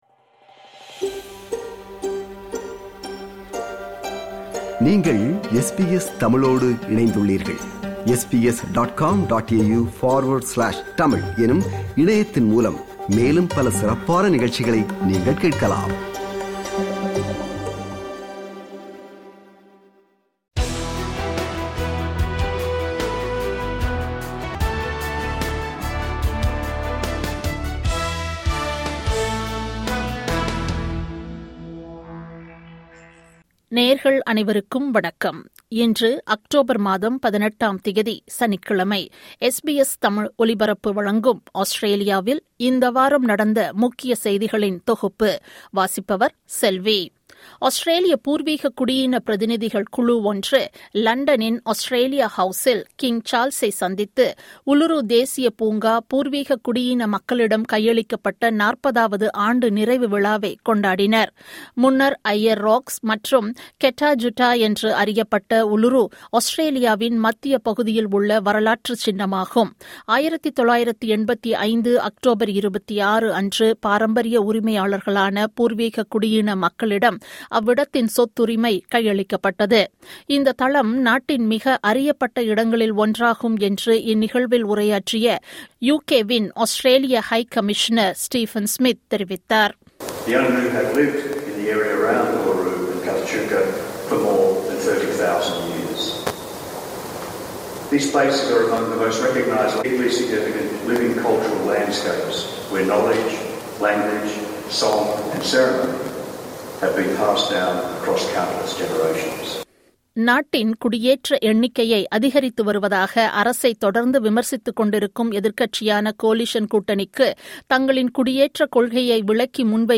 ஆஸ்திரேலியாவில் இந்த வாரம் (12 அக்டோபர் – 18 அக்டோபர் 2025) நடந்த முக்கிய செய்திகளின் தொகுப்பு.